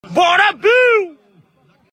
O vídeo mostra imagens de uma partida de futebol em campinho de várzea, no interior do Ceará
o narrador insistentemente chama a atenção